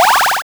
bfxr_SpellExtend.wav